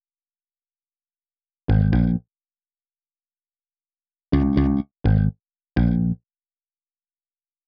Papa Bass.wav